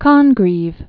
(kŏngrēv, kŏng-), William 1670-1729.